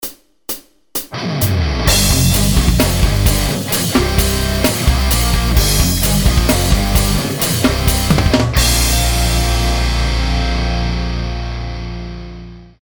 Toto aj s podkladom:
dynamika